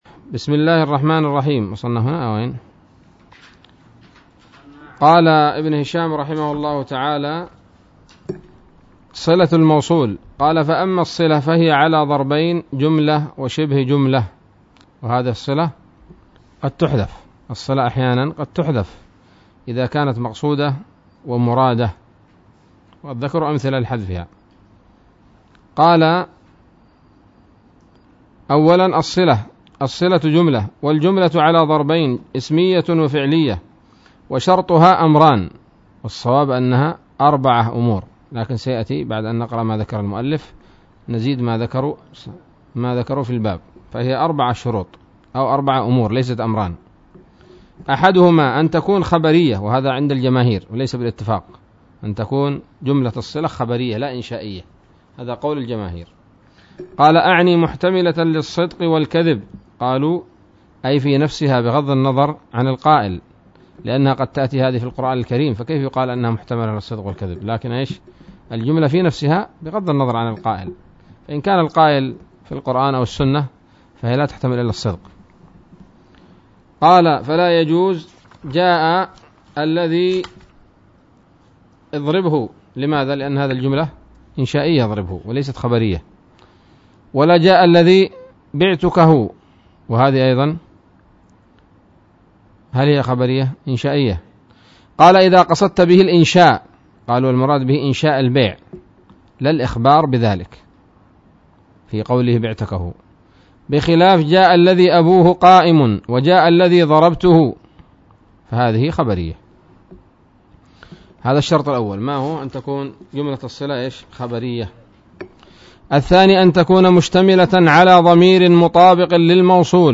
الدرس الثامن والأربعون من شرح قطر الندى وبل الصدى